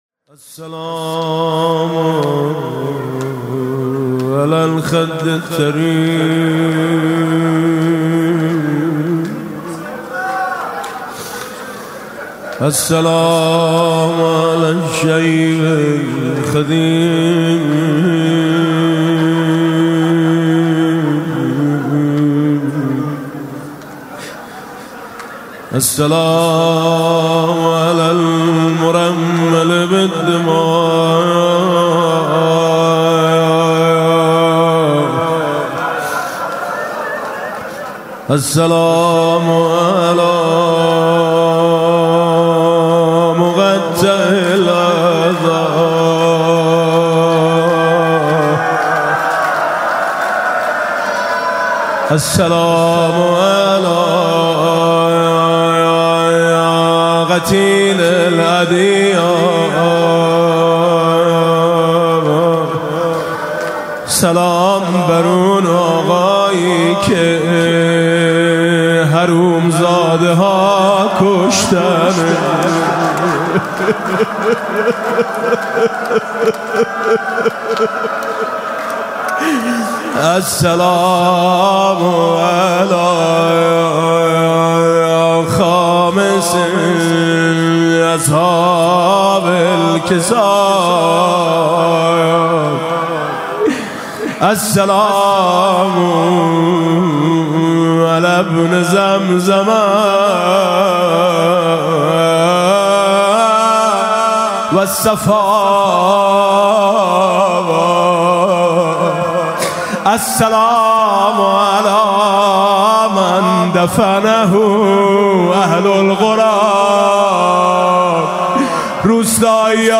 محفل عزاداری شب سوم محرم ۱۴۴۴ هیأت آیین حسینی
در مجتمع امام رضا (علیه‌السلام) برگزار شد.